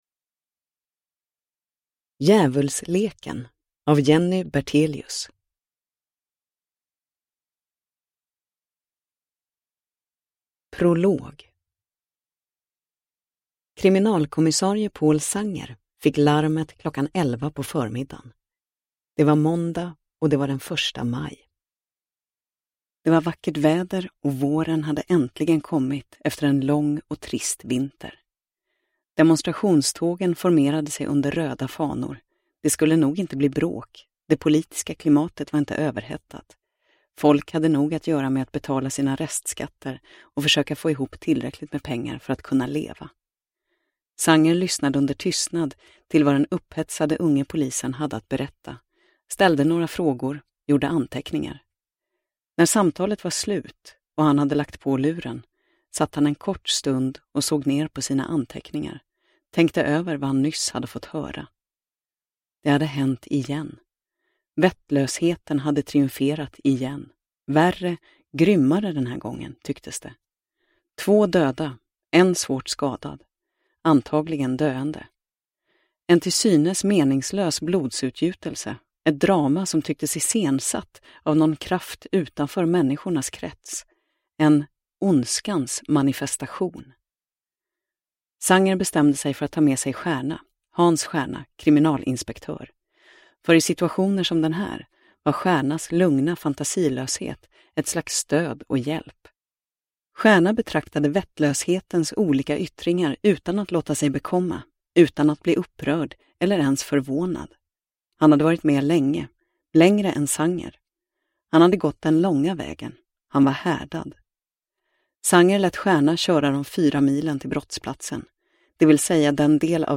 Djävulsleken – Ljudbok – Laddas ner